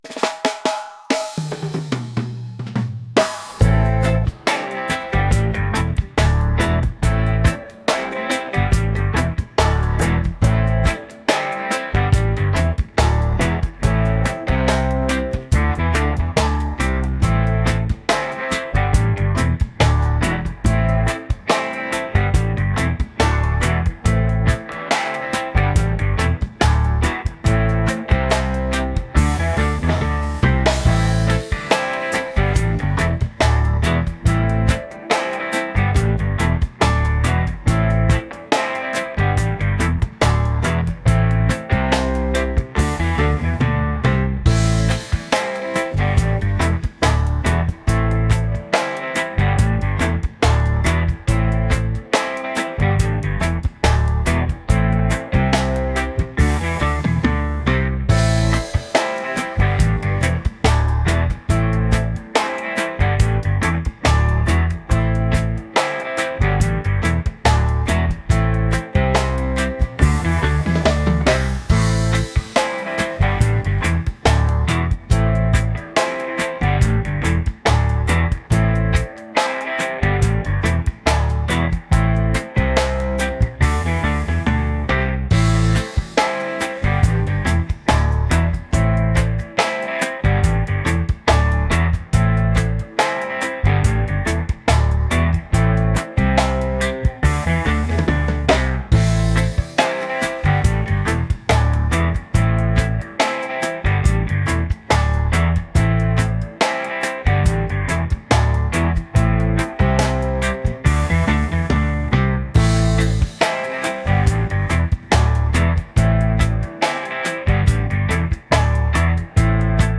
soul | reggae | laid-back